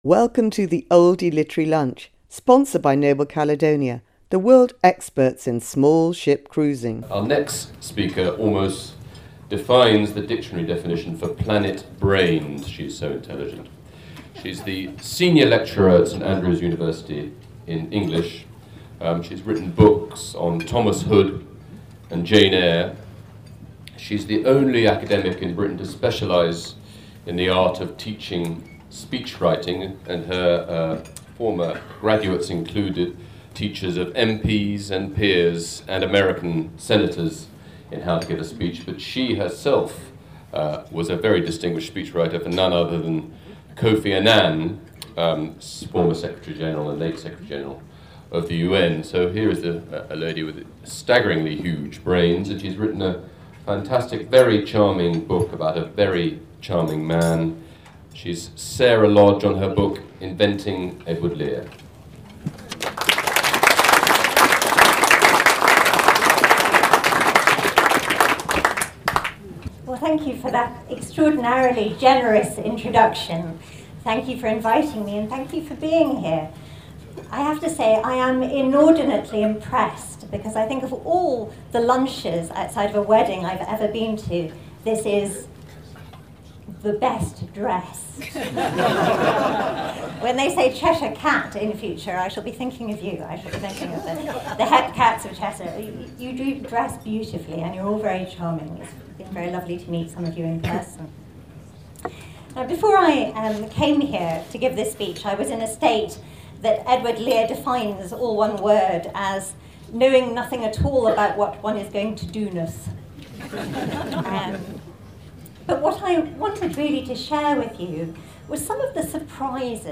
speaks at the Oldie Literary Lunch at Cholmondeley Castle